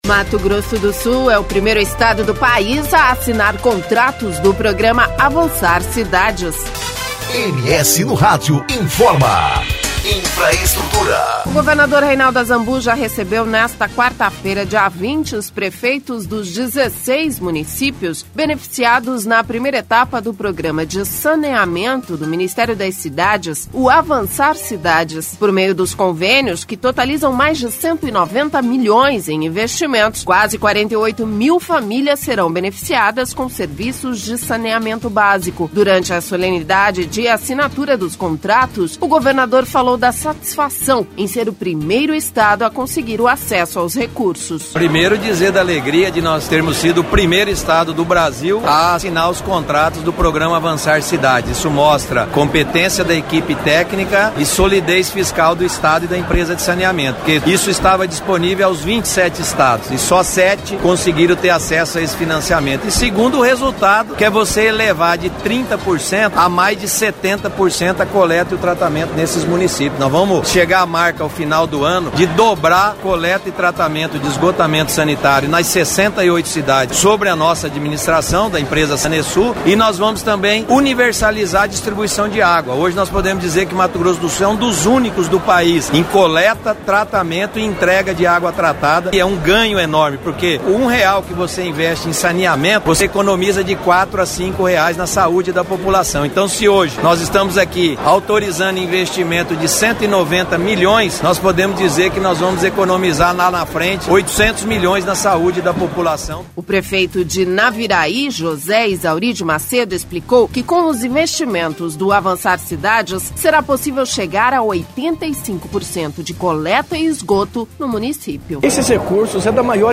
Durante a solenidade de assinatura dos contratos o governador falou da satisfação em ser o primeiro estado a conseguir o acesso aos recurso.
O Prefeito de Naviraí, José Izauri de Macedo, explicou que com os investimentos do “Avançar Cidades” será possível chegar a 85% de coleta e do esgoto.